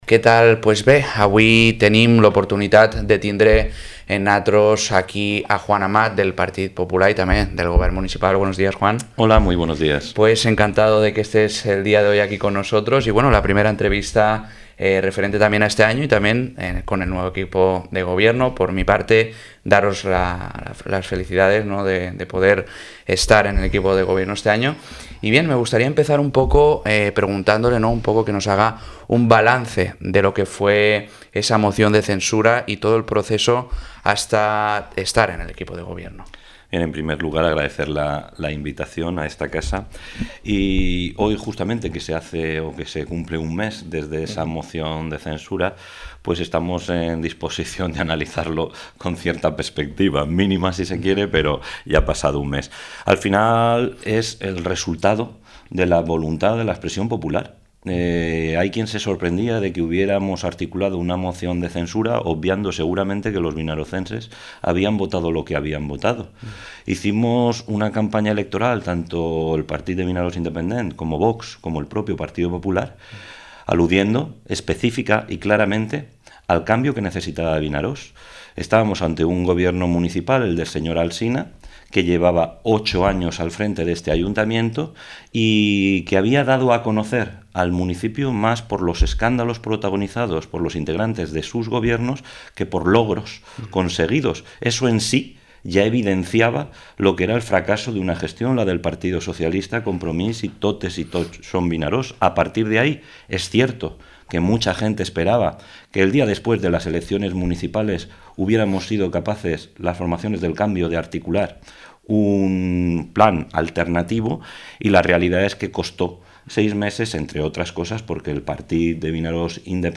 Entrevista a Juan Amat, membre de l'equip de govern de l'Ajuntament de Vinaròs